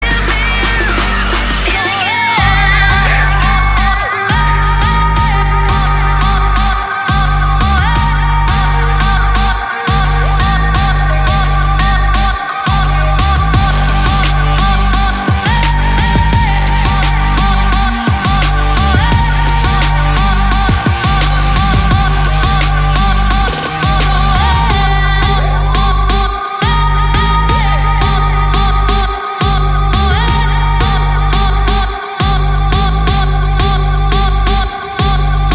• Качество: 128, Stereo
Trap
бас